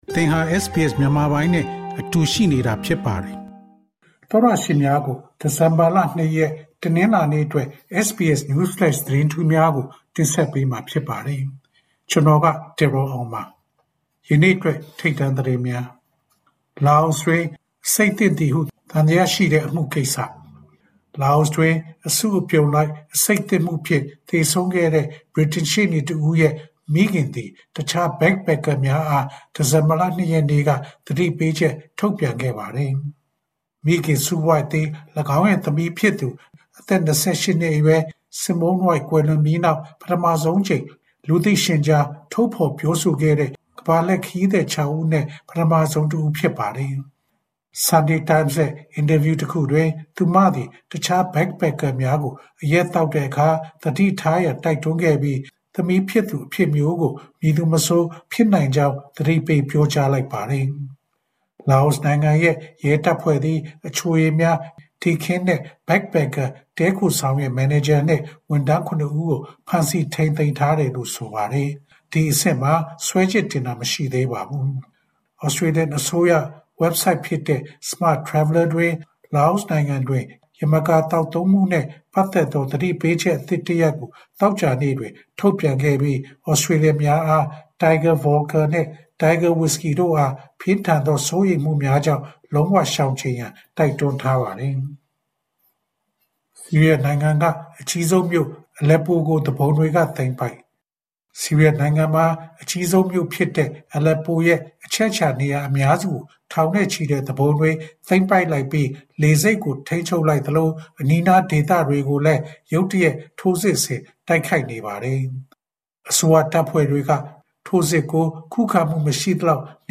ALC: SBS မြန်မာ ဒီဇင်ဘာလ ၂ ရက် News Flash သတင်းများ။